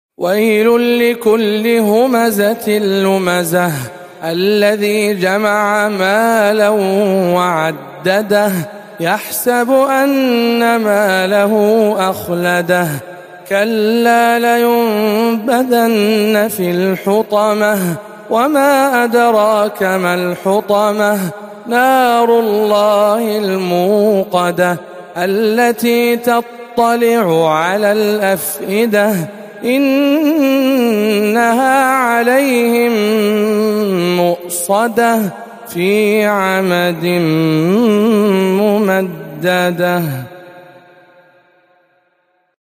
سورة الهمزة بجامع أم الخير بجدة - رمضان 1439 هـ